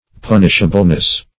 [1913 Webster] -- Pun"ish*a*ble*ness, n.